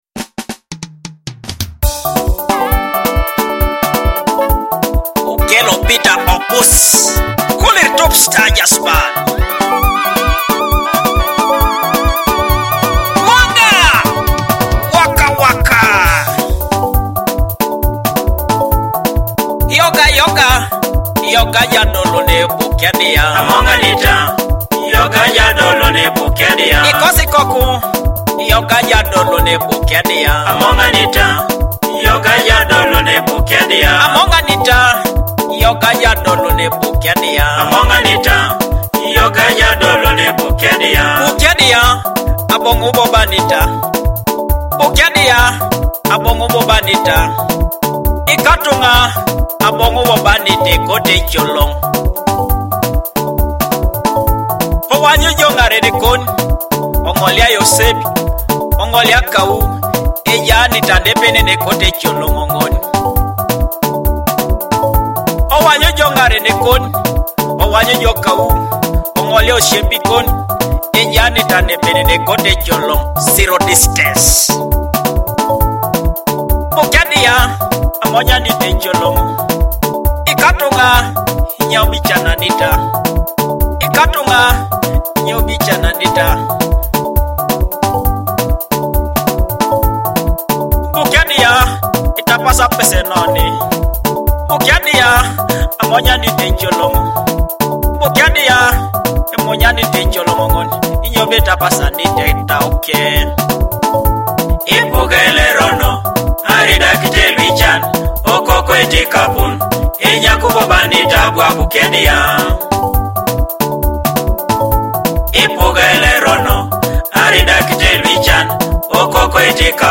Blending traditional Teso sounds with contemporary grooves